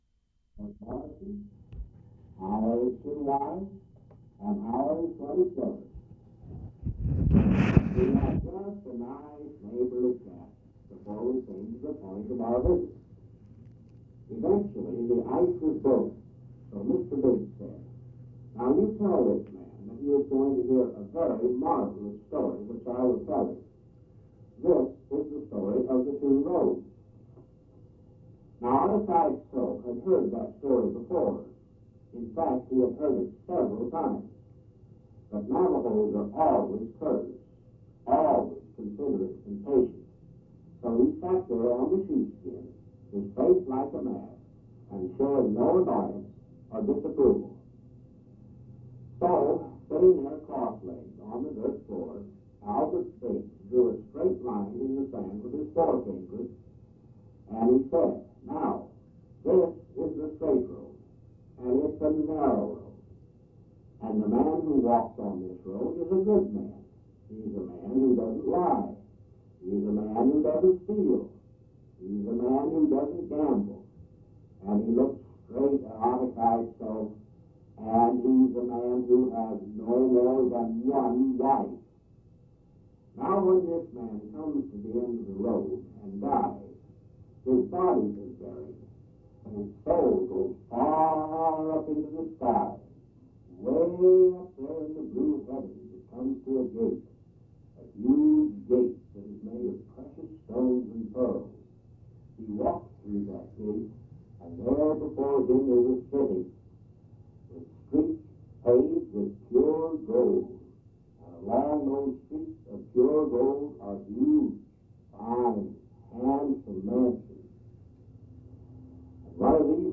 Interviews
Audio/mp3 Original Format: Audio cassette tape Resource Identifier